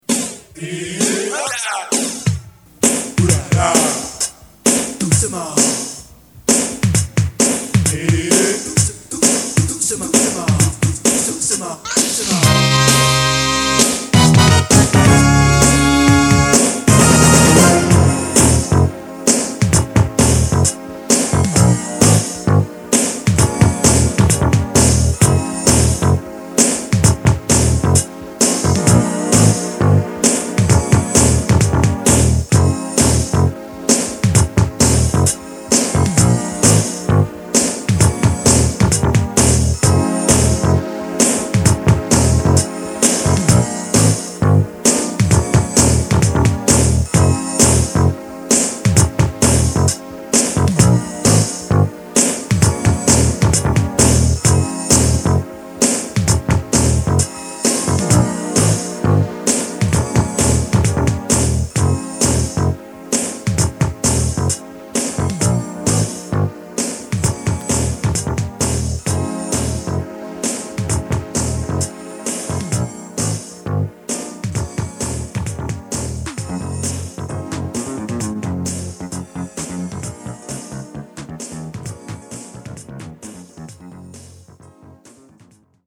Bass, drums and brass keyboard, R&B or Funk style